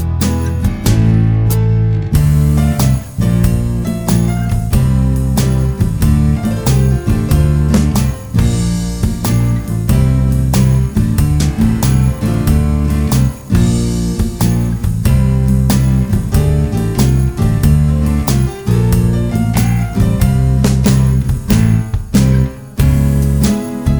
no Backing Vocals Soft Rock 4:24 Buy £1.50